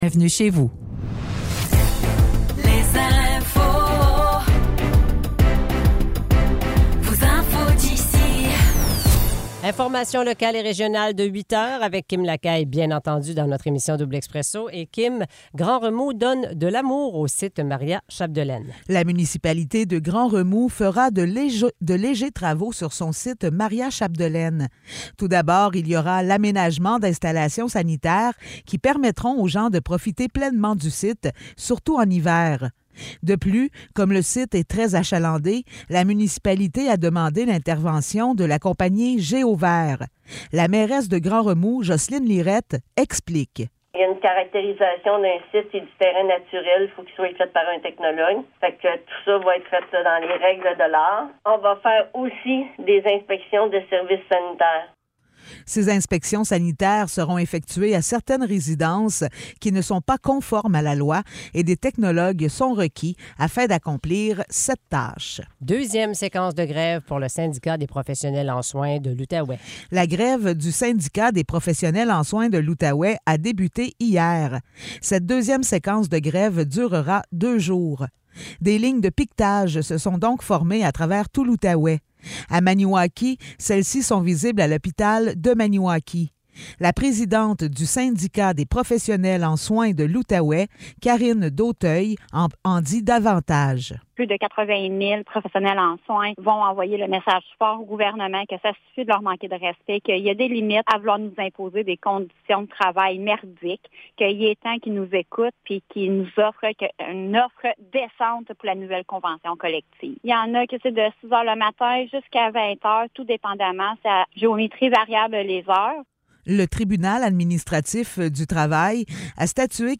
Nouvelles locales - 24 novembre 2023 - 8 h